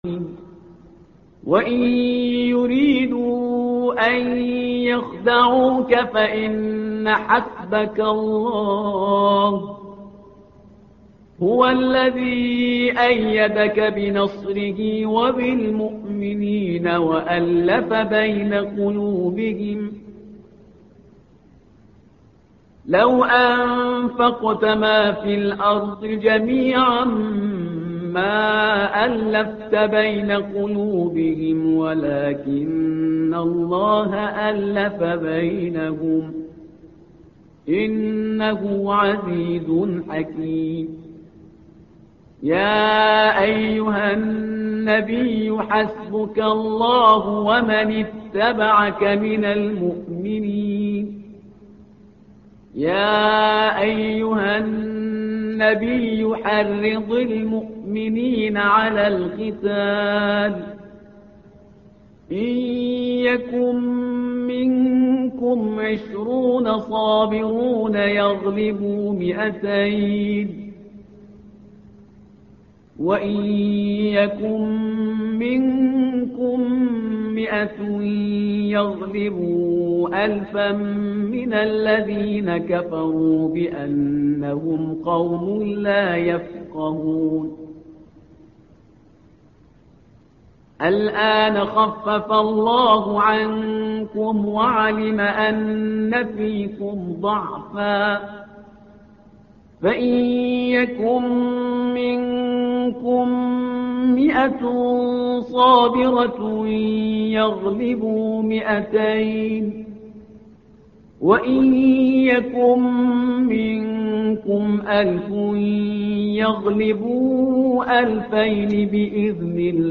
الصفحة رقم 185 / القارئ